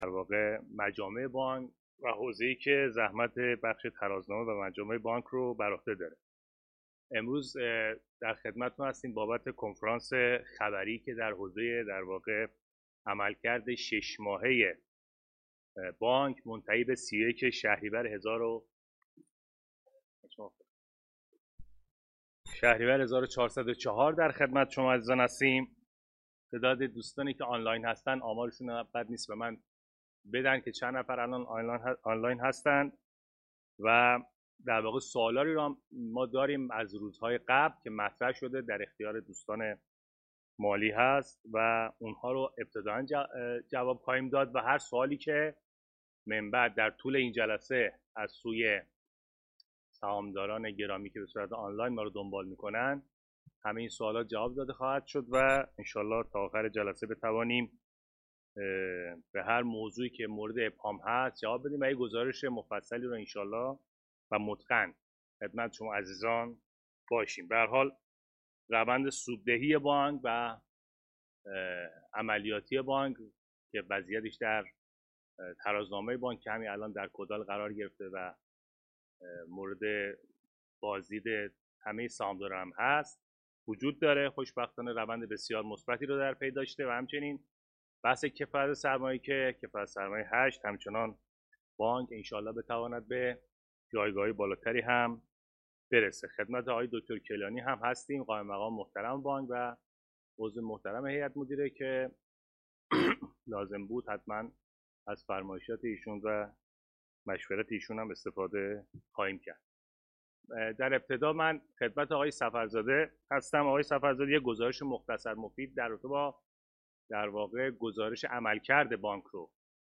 کنفرانس آنلاین پرسش و پاسخ سهامداران و مدیران شرکت بانک تجارت